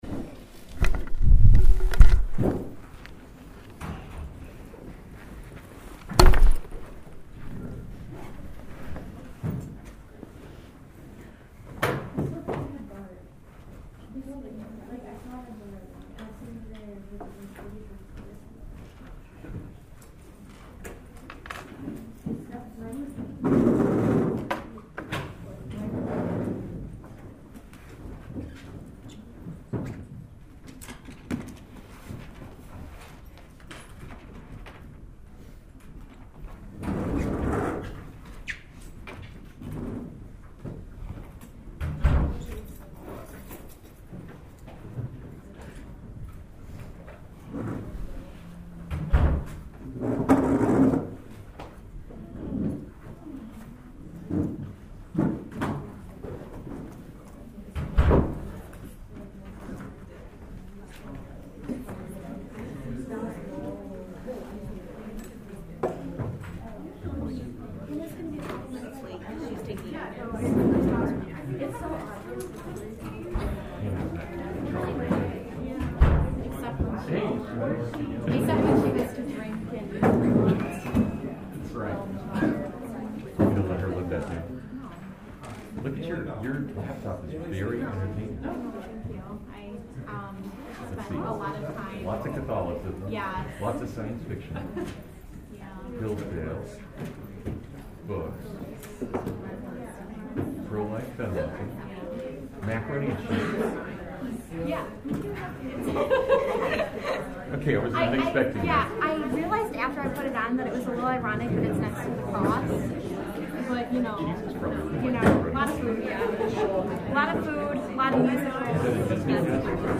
The audio quality is pretty weak, and there are a few minutes of noise and confusion at the beginning.